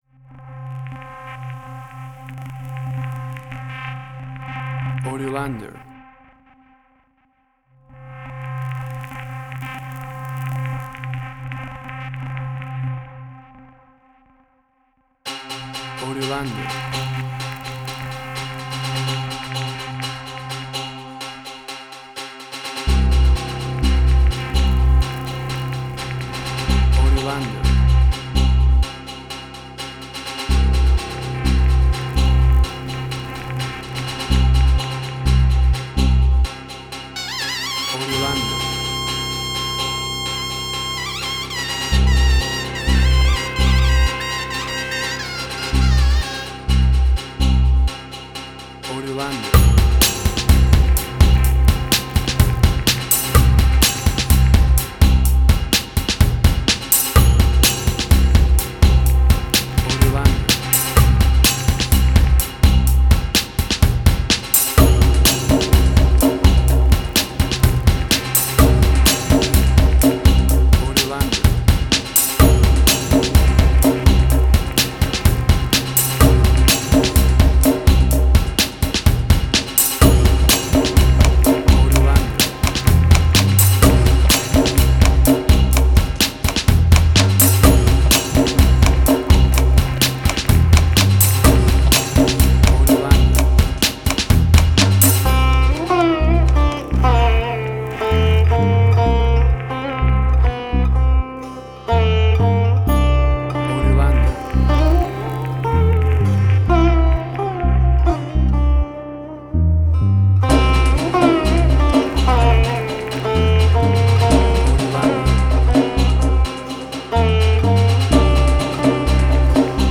Indian Fusion.
Tempo (BPM): 126